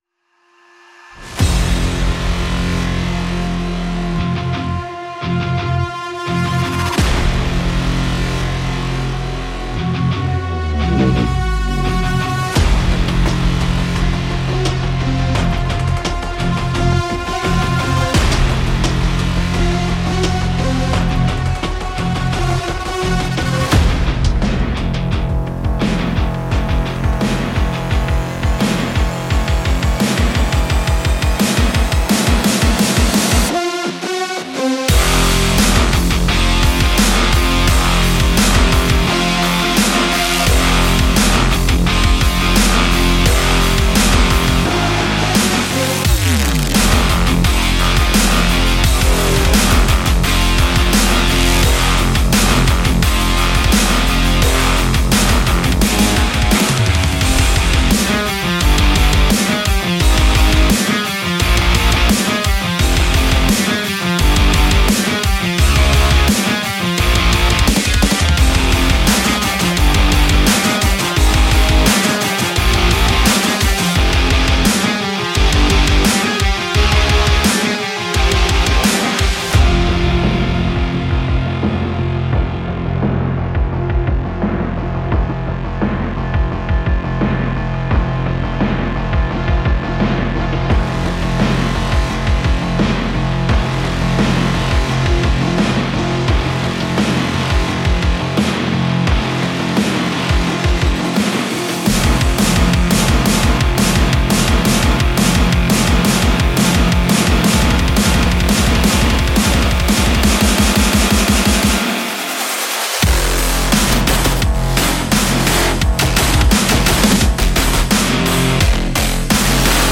Rock
Música de fondo para video hard rock